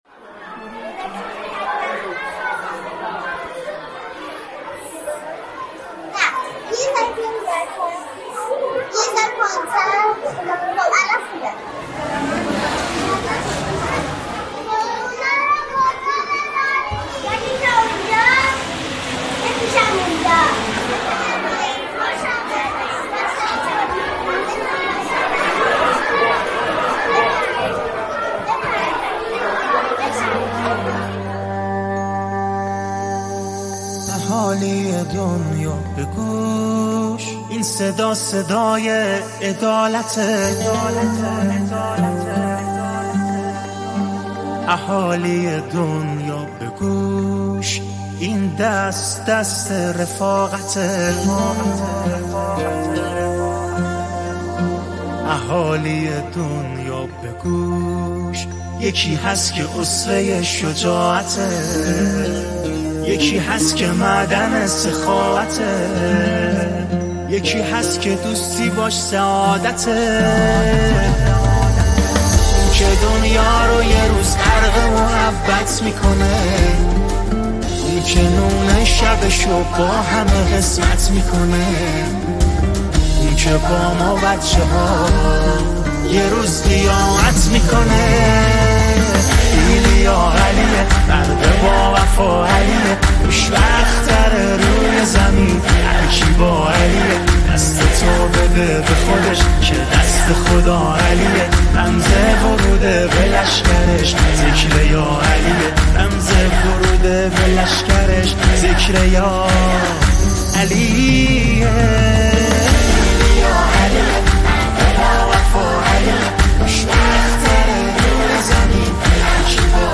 نماهنگ زیبا و دلنشین